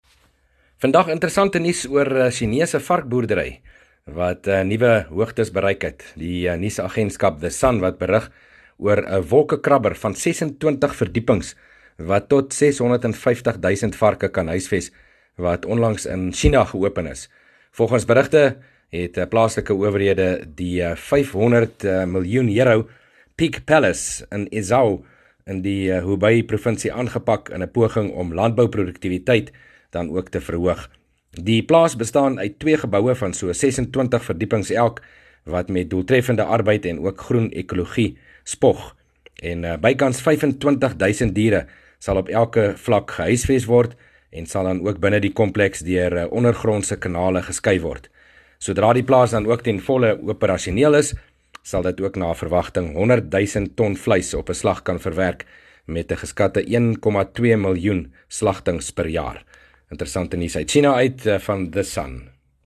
berig oor ‘n unieke varkboerdery konsep wat in China van stapel gestuur is